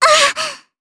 Kirze-Vox_Damage_jp_03.wav